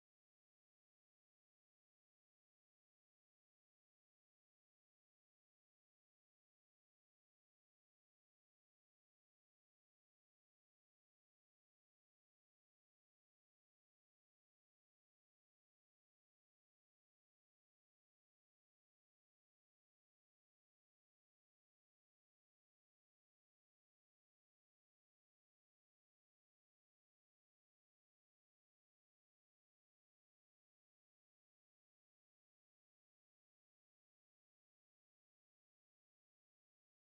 Enfantines - rondes et jeux
Répertoire de chansons populaires et traditionnelles
Pièce musicale inédite